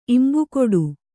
♪ imbukoḍu